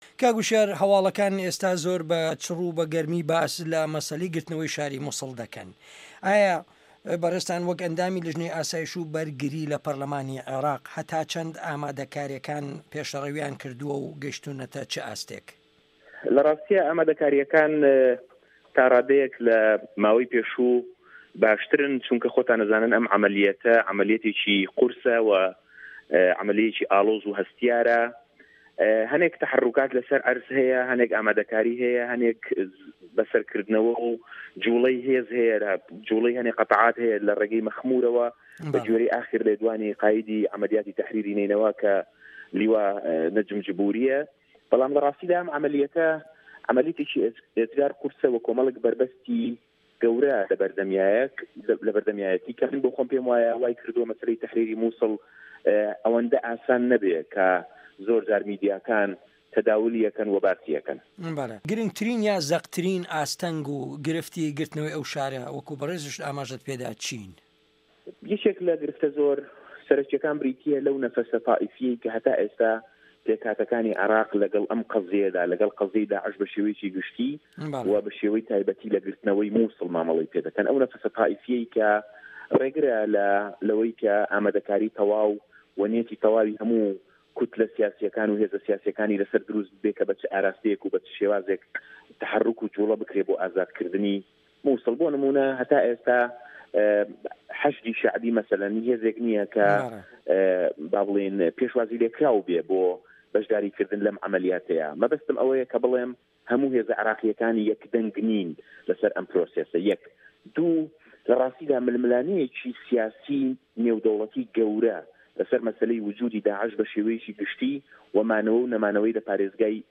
وتوێژ لەگەڵ هوشیار عەبدوڵا